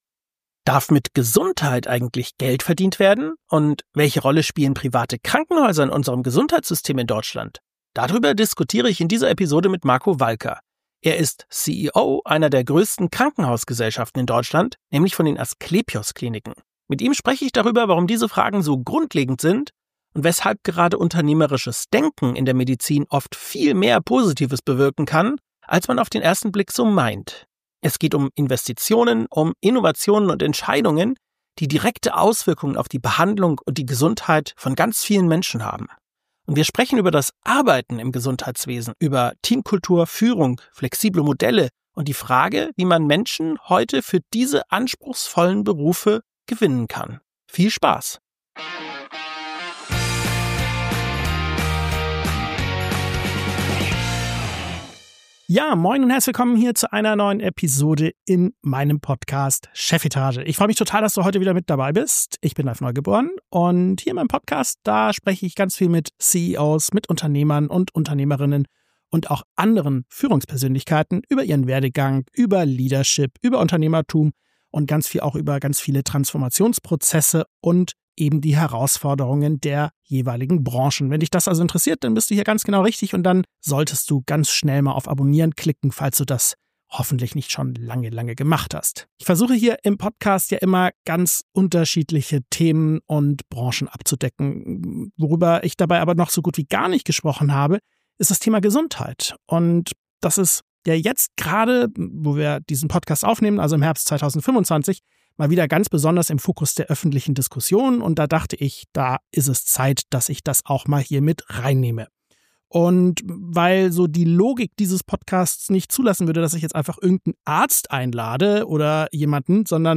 91 ~ Chefetage - CEOs, Unternehmer und Führungskräfte im Gespräch Podcast